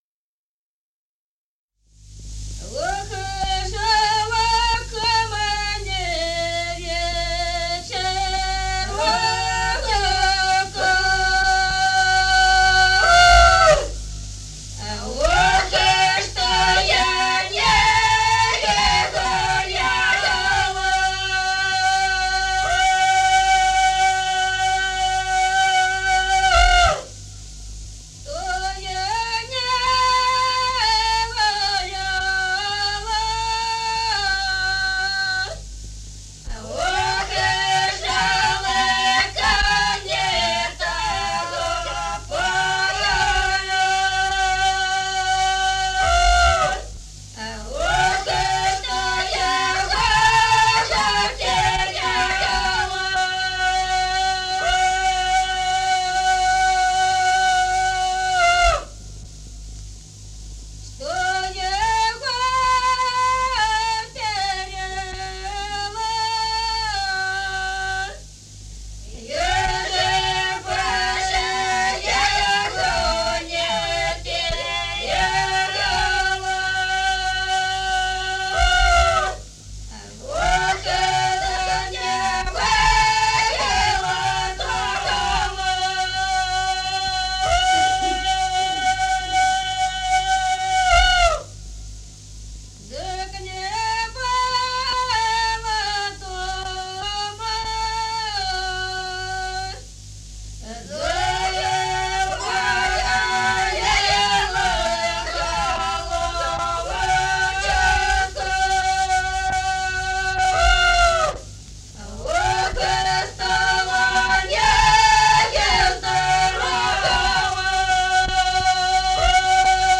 Песни села Остроглядово. Ох, жалко мне вечерочка (жнивная).